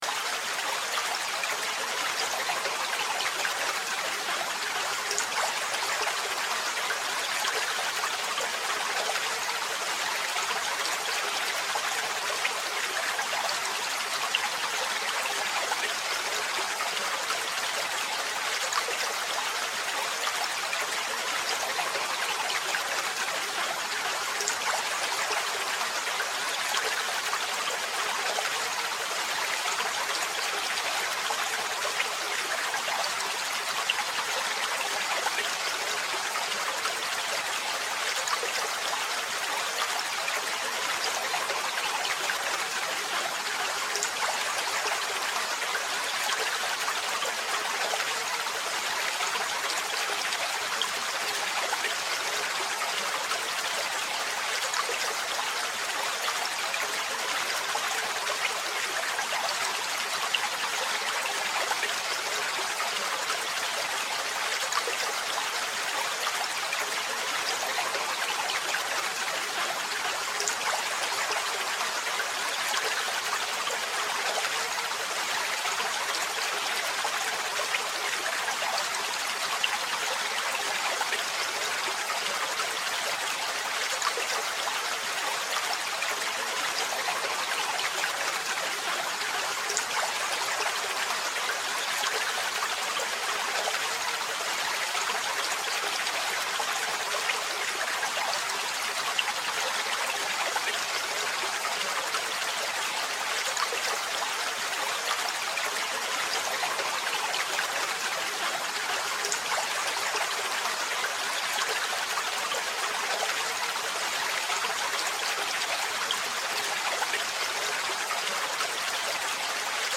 Gentle Stream Whisper: Light Water Flow for Stress Relief (1 Hour)
Before you press play, you should know this: all advertisements for Rain Sounds, Rain To Sleep, Rainy Day, Raining Forest, Rainy Noise are placed gently at the very beginning of each episode.